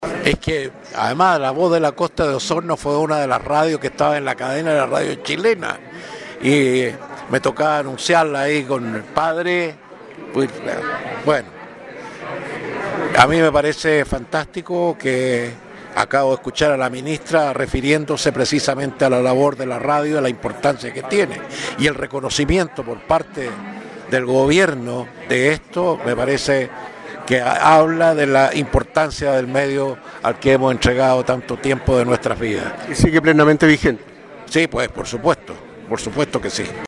En la ceremonia estaba presente Miguel Davagnino, figura legendaria de la radiotelefonía y televisión nacional, con más de 60 años de trayectoria y programas que dejaron huella, tuvo lindos recuerdos de radio La Voz de la Costa